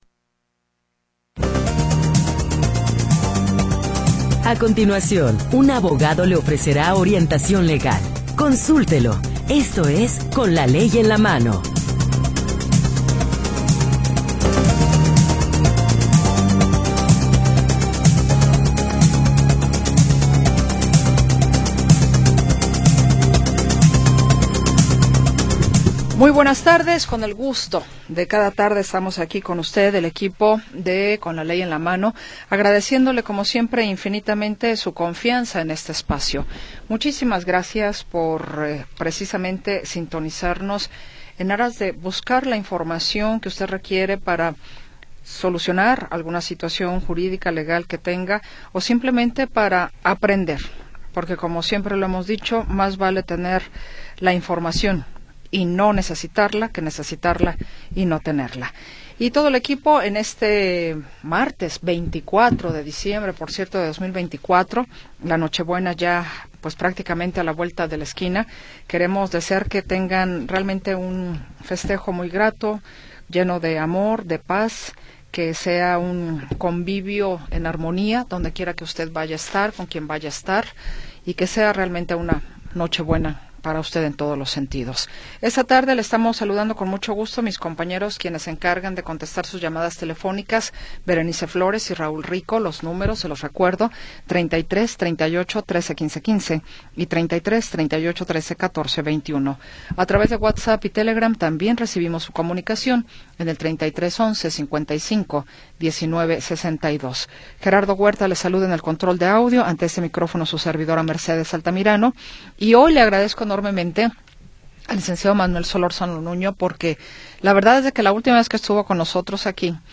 Orientación legal de jueces y abogados especialistas